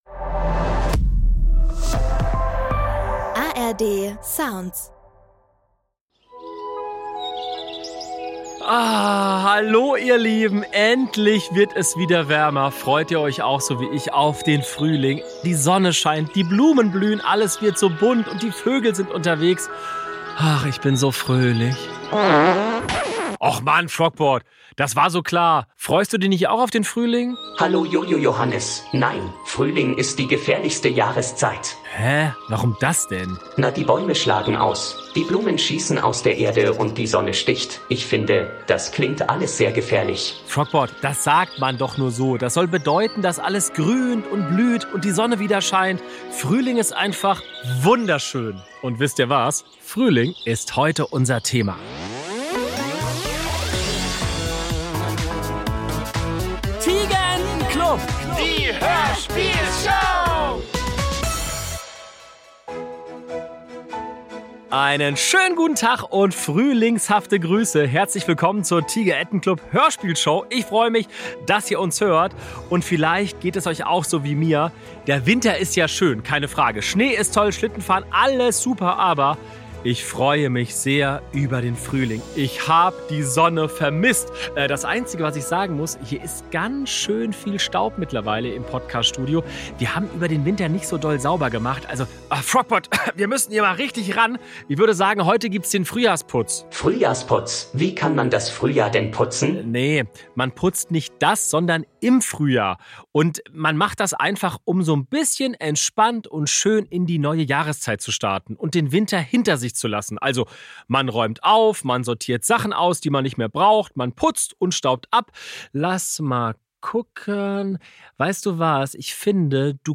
Wer kennt mehr Blumenarten oder Obstsorten? Hört rein und ratet mit - oder ist Frogbots Laubbläser zu laut?